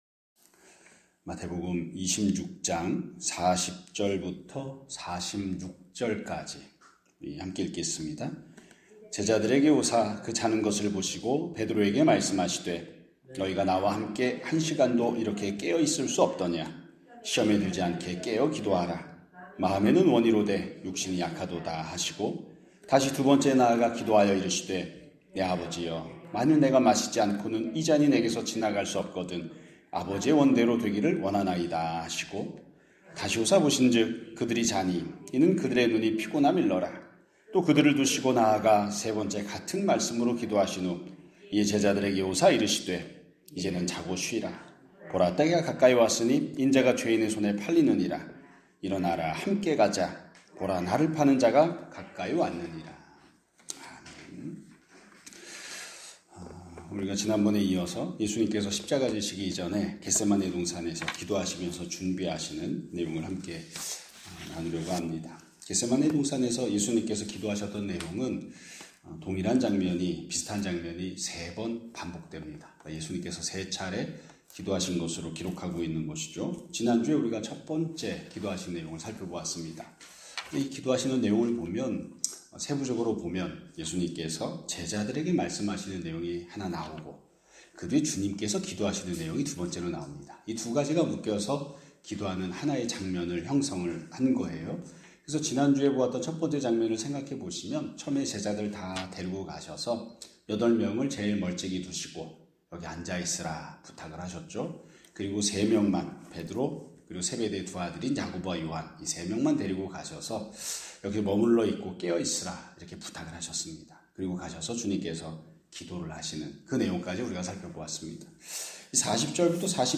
2026년 4월 6일 (월요일) <아침예배> 설교입니다.